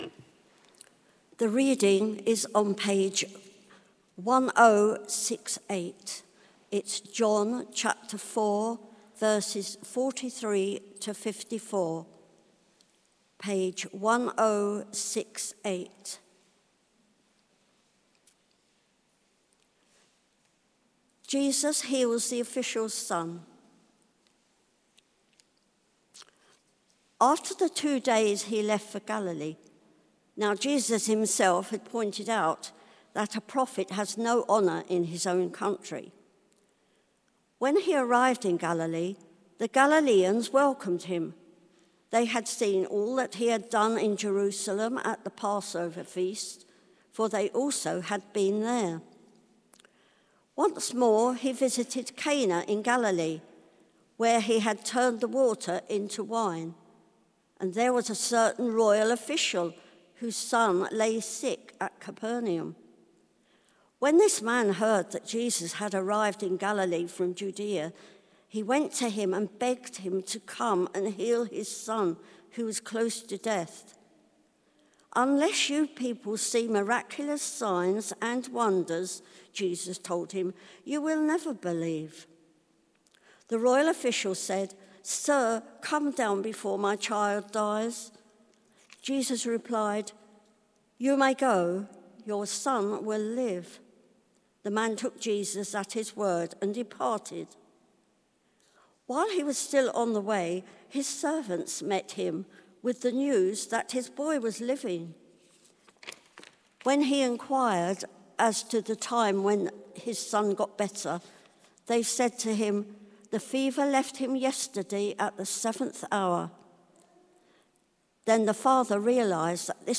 Media for Service (10.45) on Sun 20th Oct 2024 10:45